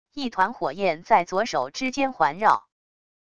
一团火焰在左手之间环绕wav音频